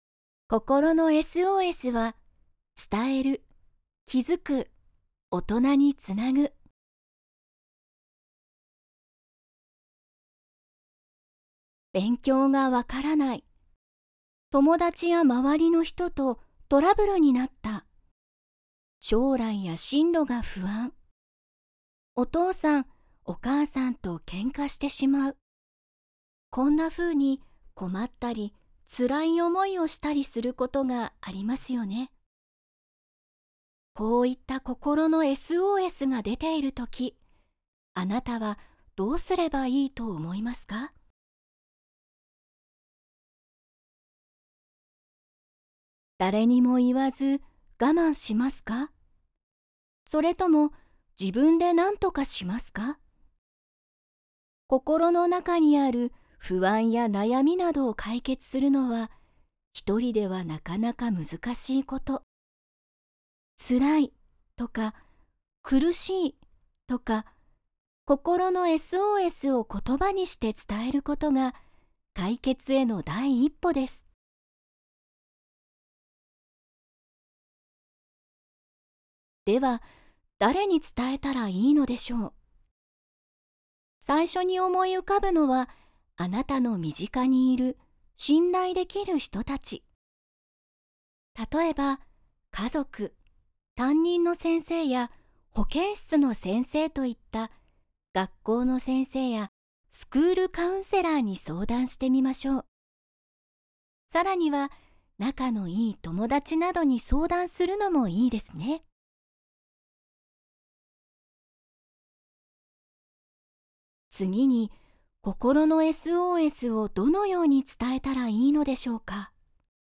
4-2_特別支援用資料音声データ（読み聞かせ用）